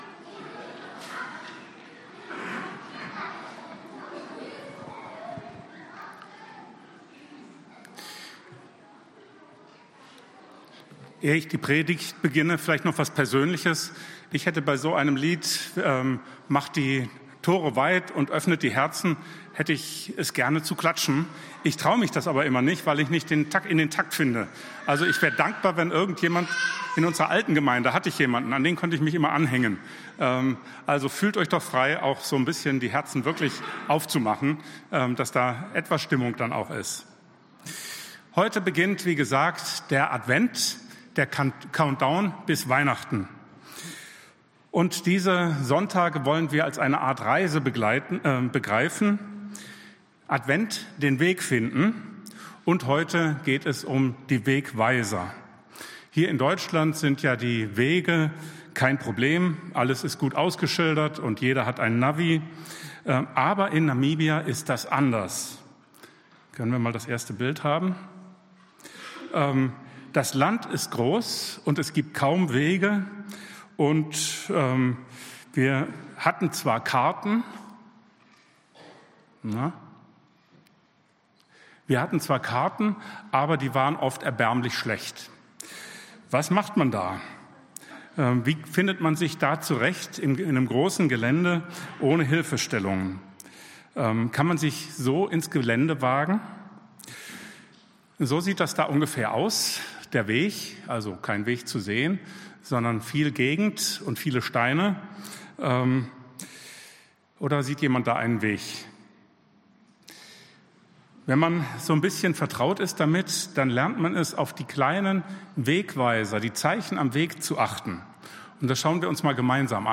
EFG-Haiger Predigt-Podcast 1.